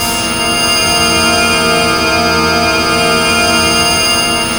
A#4 BOWED07L.wav